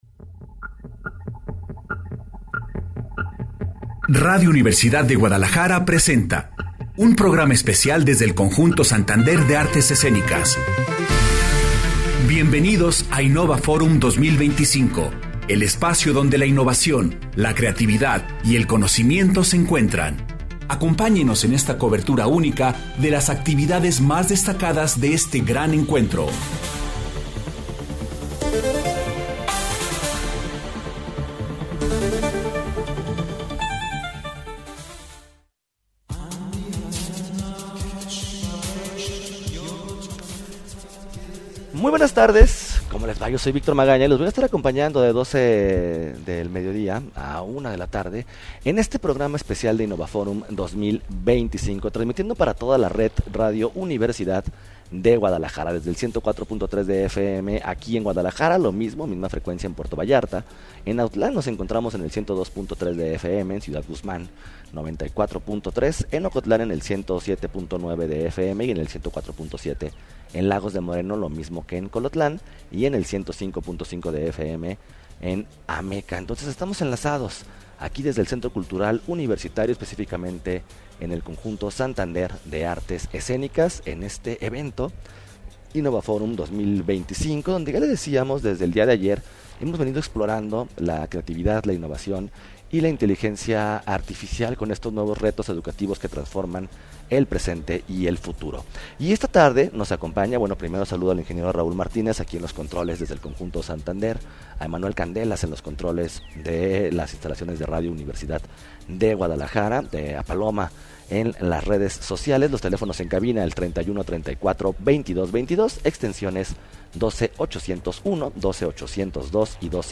Continuamos con nuestros invitados especiales.